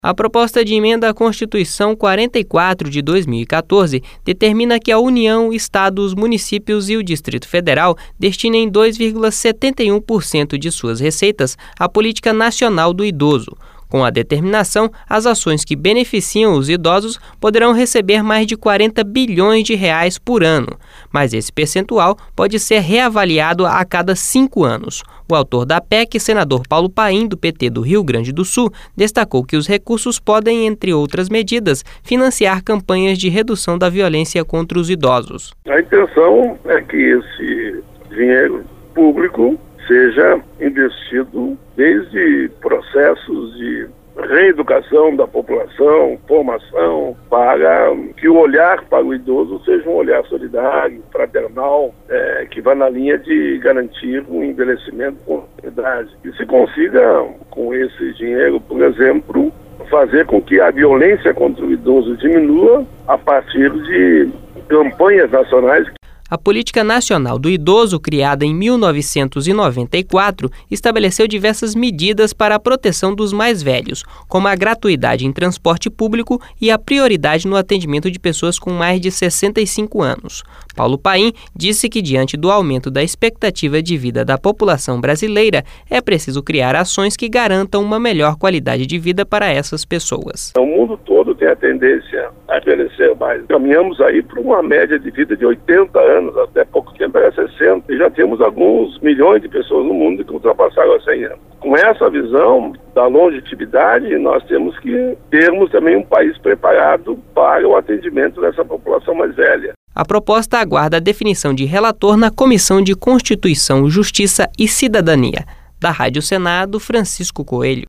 Senador Paulo Paim